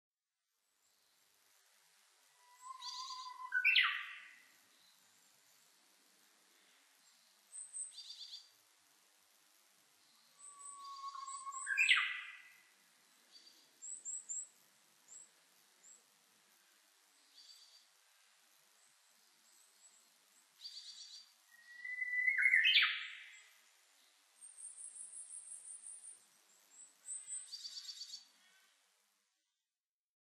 ウグイス　Cettia diphoneウグイス科
日光市稲荷川中流　alt=730m  HiFi --------------
Mic.: Sound Professionals SP-TFB-2  Binaural Souce
他の自然音：　 カラ類